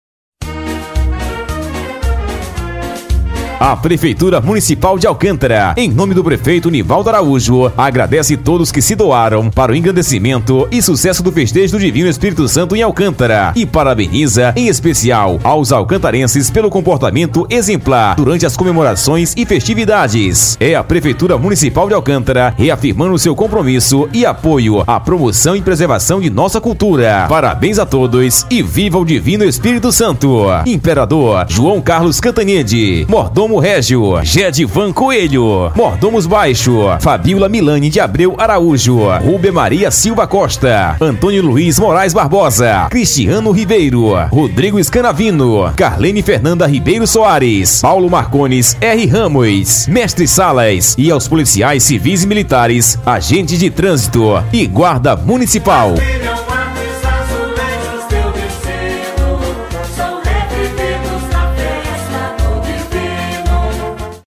O prefeito Nivaldo emitiu uma nota de agradecimento a população alcantarense pelo sucesso do festejo este ano, confira: